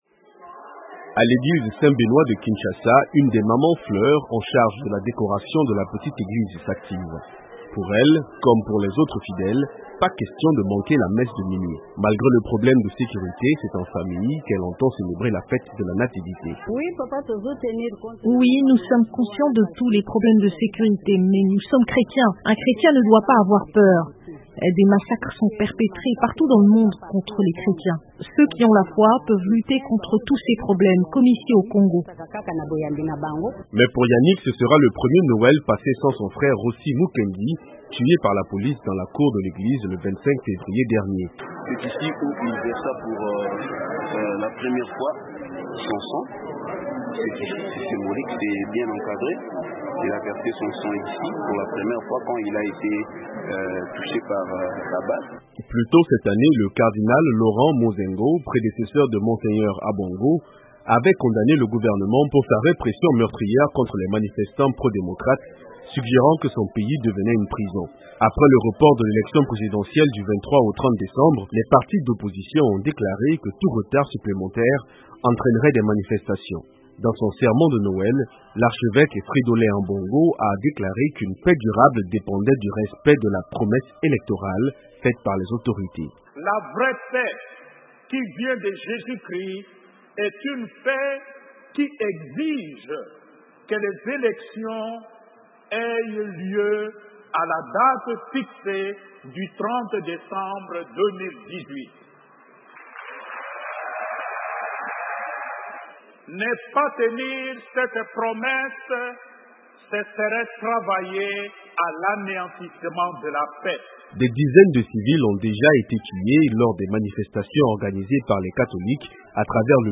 A l’instar des chrétiens du monde entier, on a célébré la naissance de l’enfant jésus en RDC malgré le climat tendu qui règne dans le pays. Les paroissiens de la cathédrale Notre-Dame-de-Kinshasa ont écouté l'Archevêque Fridolin Ambongo lancer un appel à la paix.